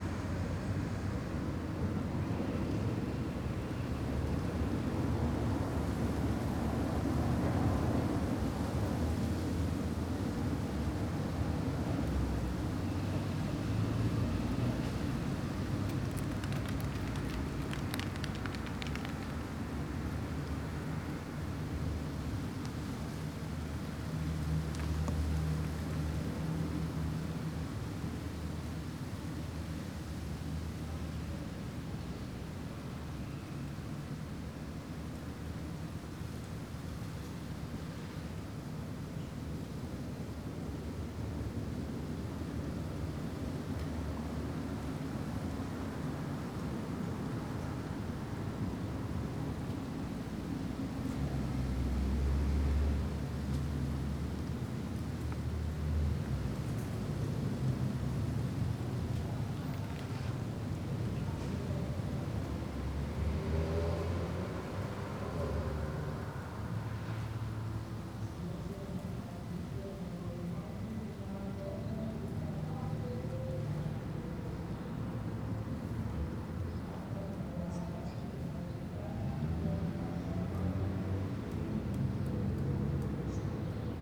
CSC-04-222-LE - Ambiencia praca da maconaria vento leve nas arvores, pouco movimento, folhas secas, transito longe.wav